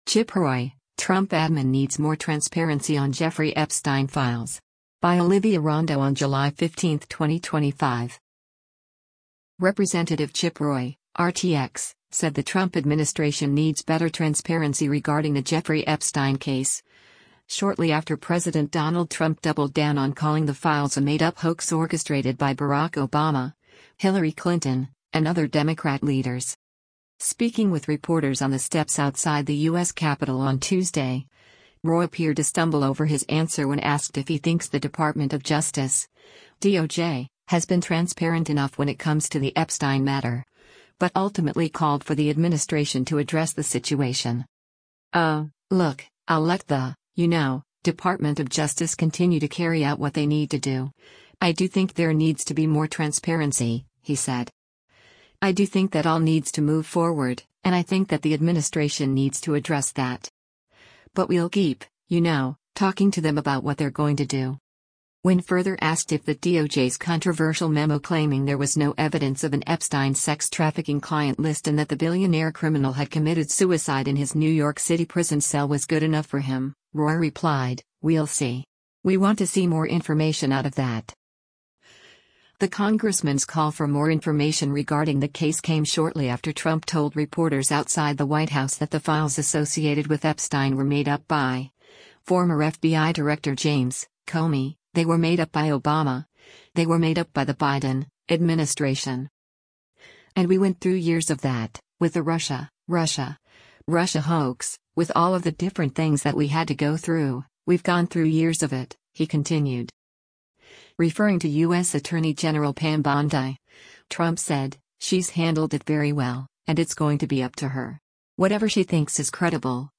Speaking with reporters on the steps outside the U.S. Capitol on Tuesday, Roy appeared to stumble over his answer when asked if he thinks the Department of Justice (DOJ) has “been transparent enough when it comes to the Epstein matter,” but ultimately called for the administration to “address” the situation: